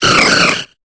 Cri de Jirachi dans Pokémon Épée et Bouclier.